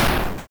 bang.wav